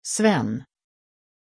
Aussprache von Sven
pronunciation-sven-sv.mp3